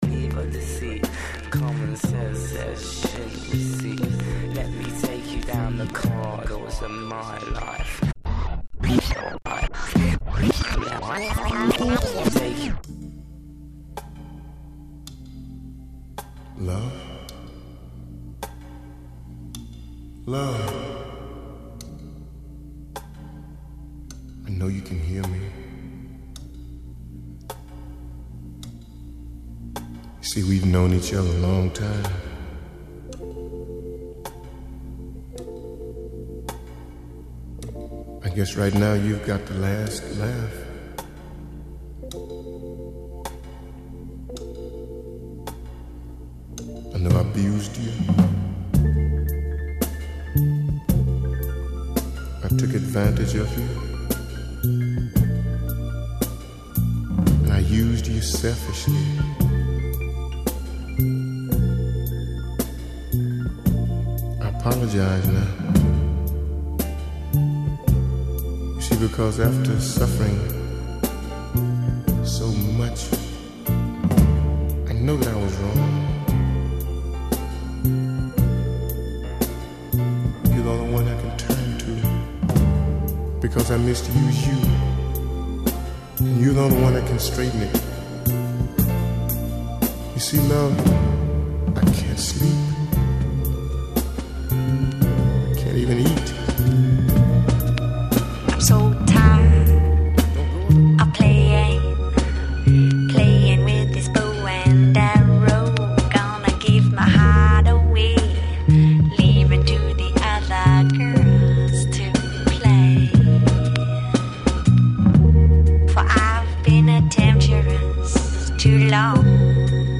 Diminuí a velocidade neste set.